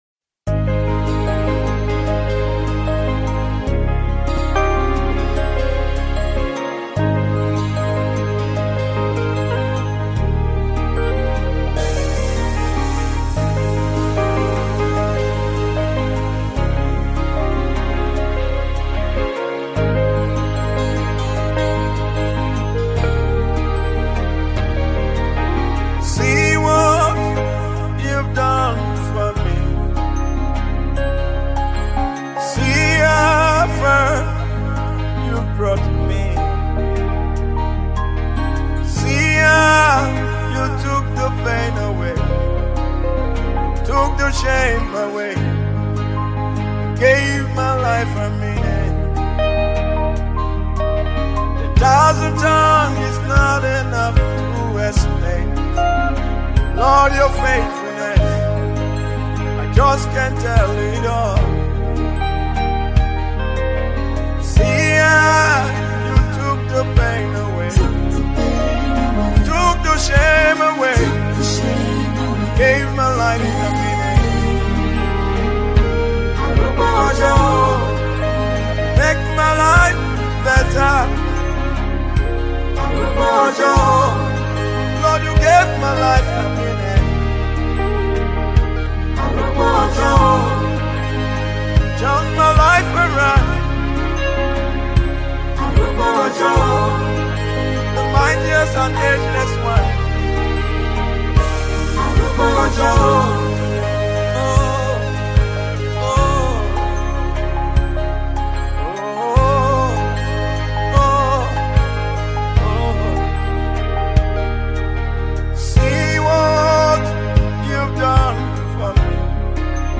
Nigeria Gospel Music
unveils a powerful new worship anthem